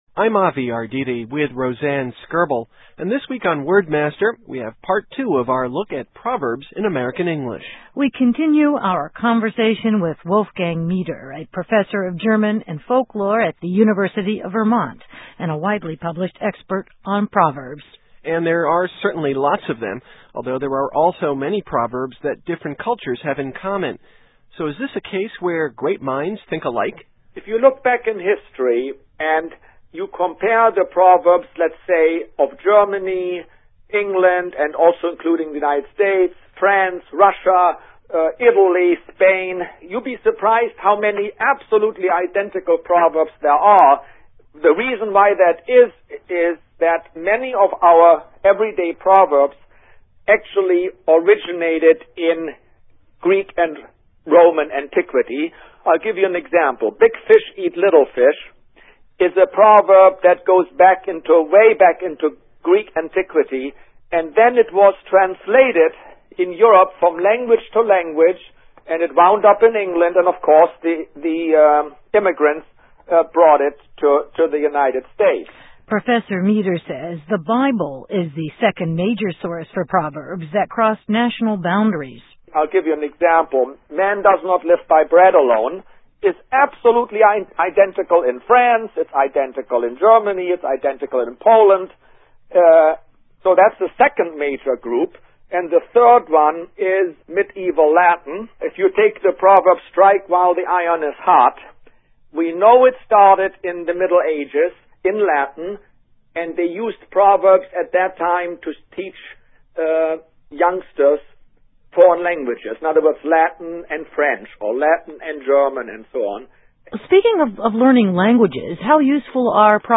Broadcast: November 17, 2004